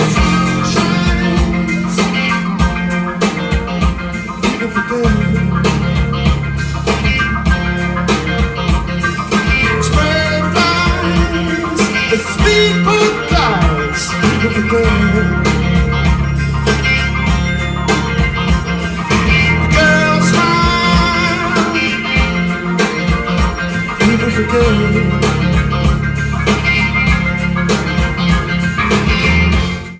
Comments: Very good mono soundboard recording*.